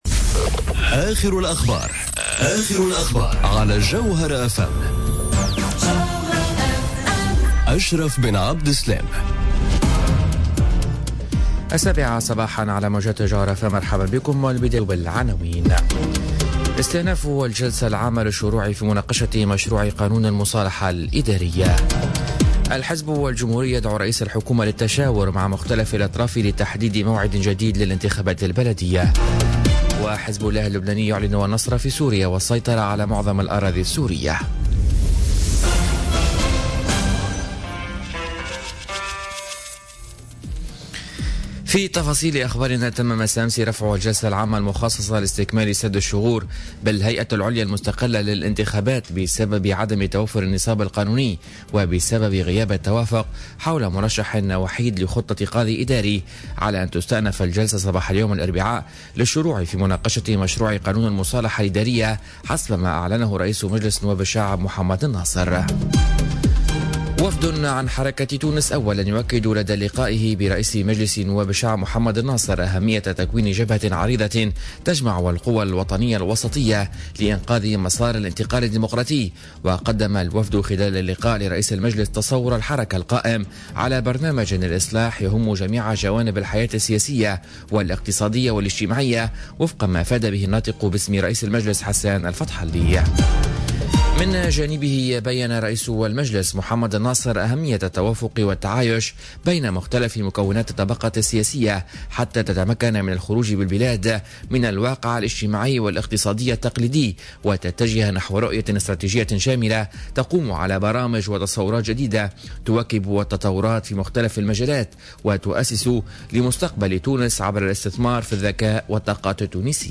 نشرة أخبار السابعة صباحا ليوم الإربعاء 13 سبتمبر 2017